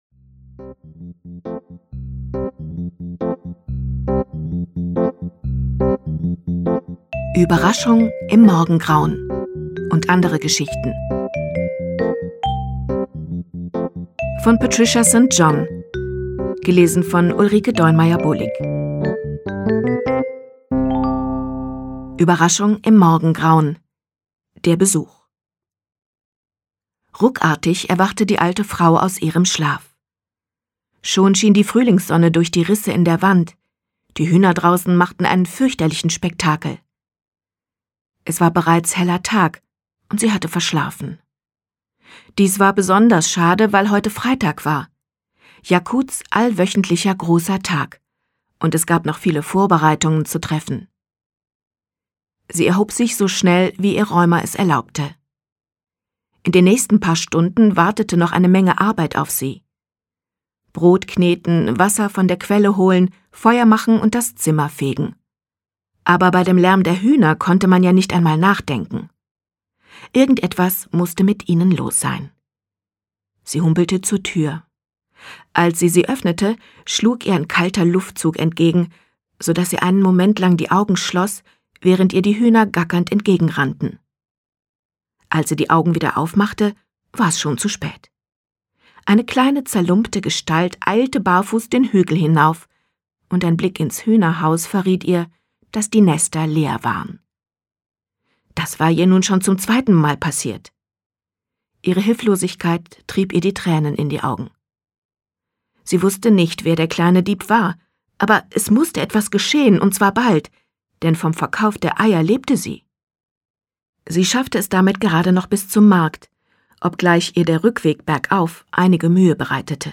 St.John: Überraschung im Morgengrauen (MP3-Hörbuch)